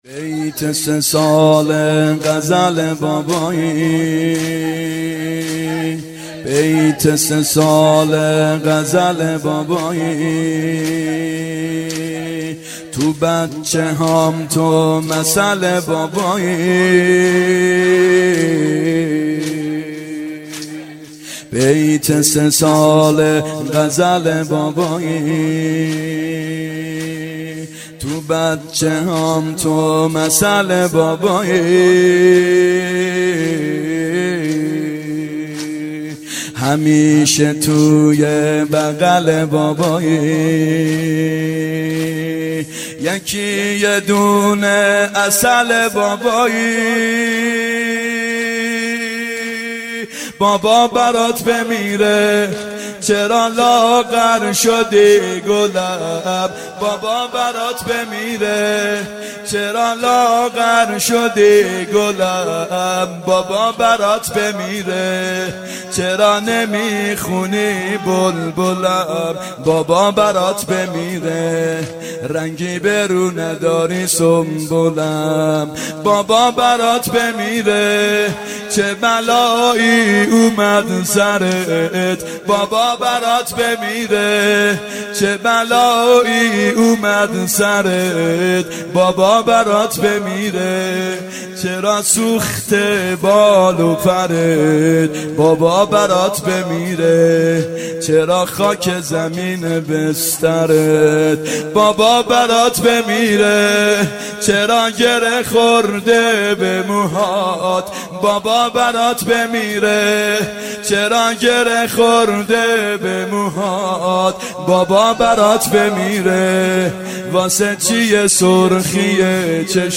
محرم 91 ( هیأت یامهدی عج)